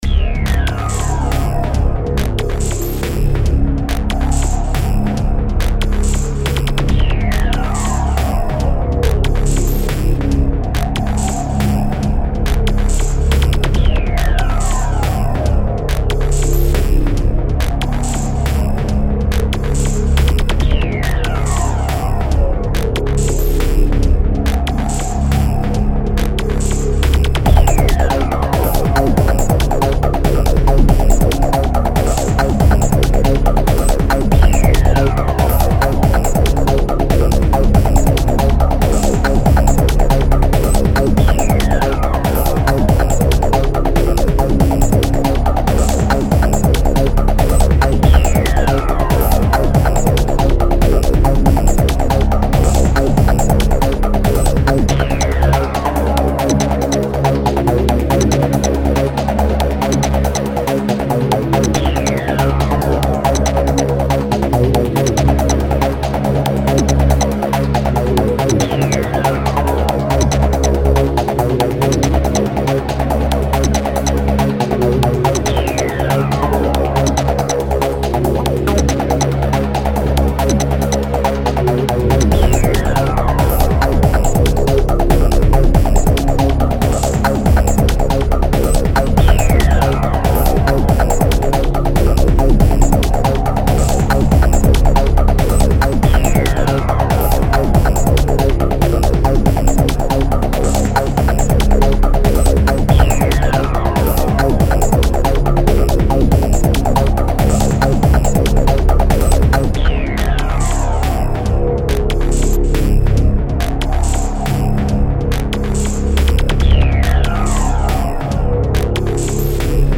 This "archive" consists music in very old tower defence.